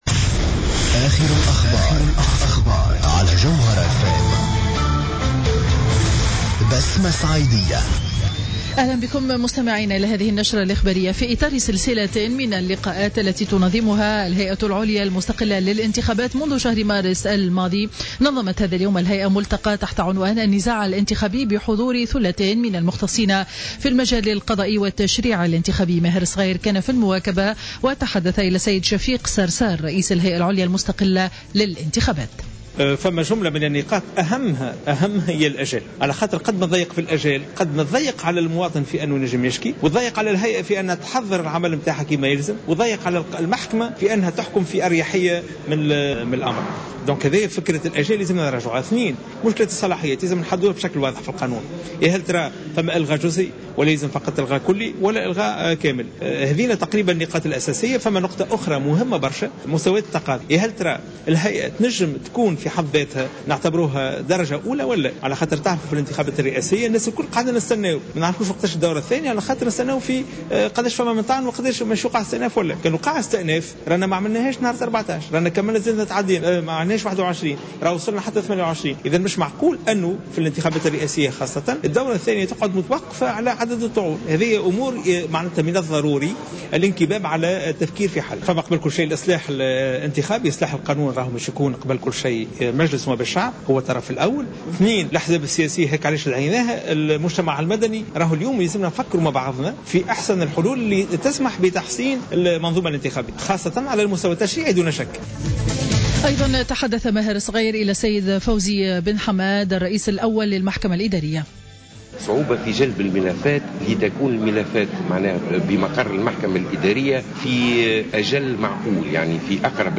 نشرة أخبار منتصف النهار ليوم الإربعاء 10 جوان 2015